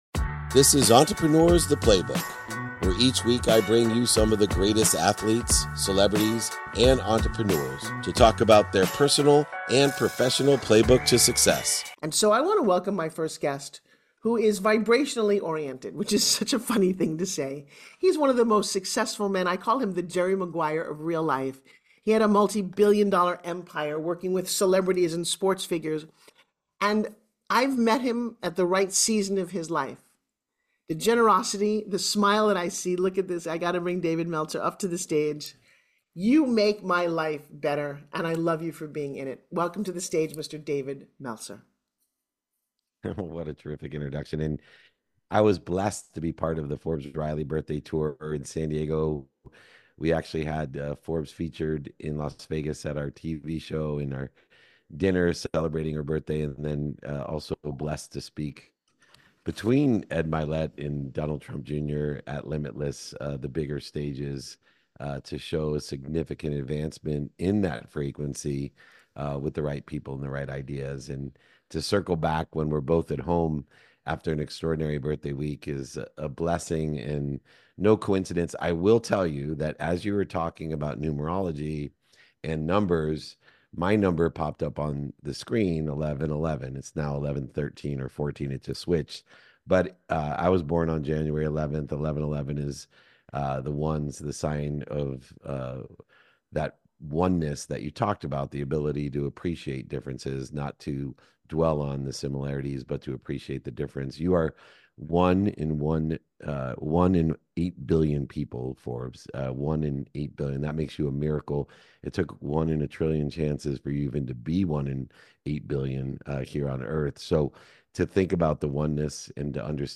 In today's episode, I sit down with Forbes Riley, an award-winning TV host, author, motivational speaker, and entrepreneur. We discuss the significance of aligning your purpose with your professional journey, the power of consistency, and the importance of knowing your values. Forbes shares her journey from hosting the original X Games to becoming a national talk show host, highlighting the impact of her unique experiences.